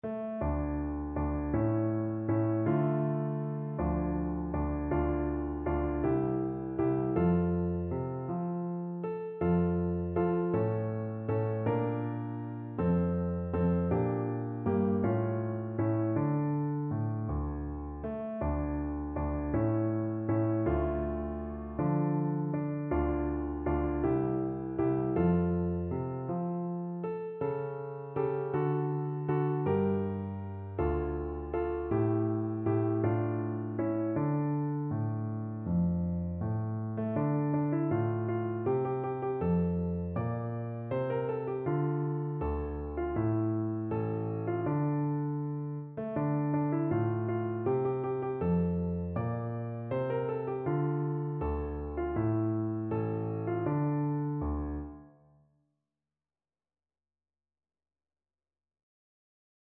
No parts available for this pieces as it is for solo piano.
6/8 (View more 6/8 Music)
Andante
Piano  (View more Easy Piano Music)